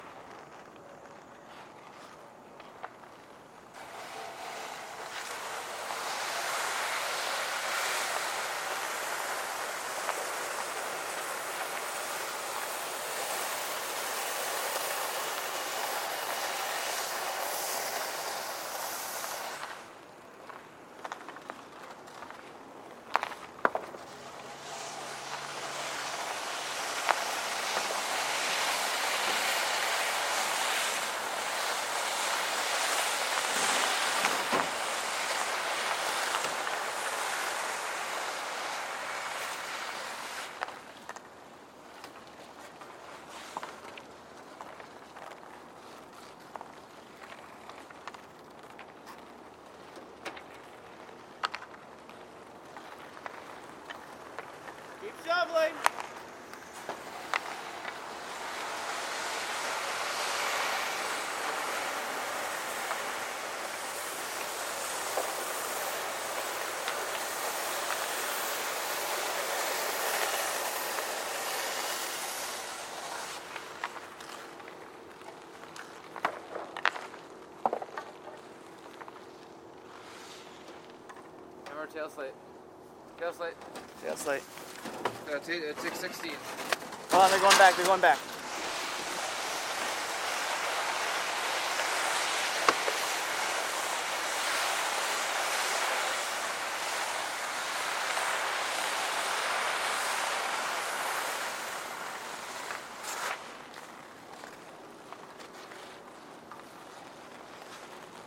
俄罗斯 " 冰球比赛的空位奖杯
描述：业余联赛曲棍球比赛。看台上没有人。玩家说俄语.ZOOM H6 MS麦克风
标签： 安静 曲棍球 游戏
声道立体声